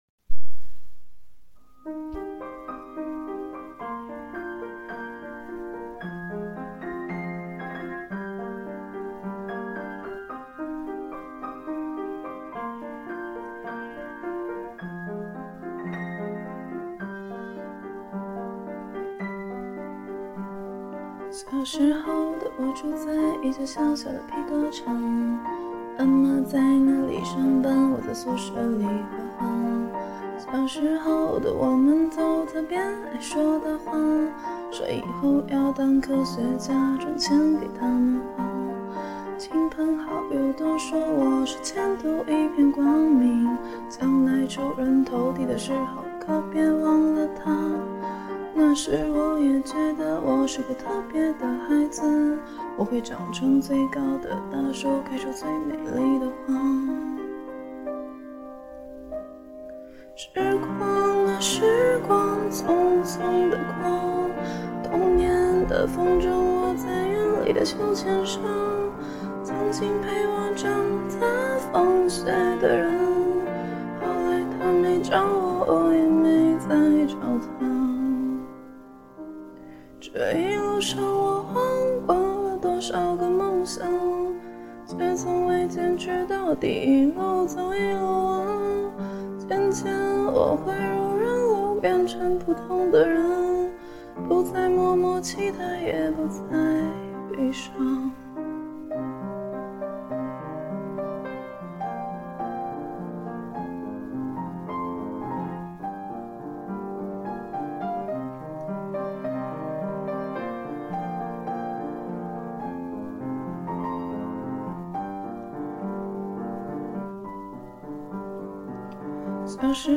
曲风：民谣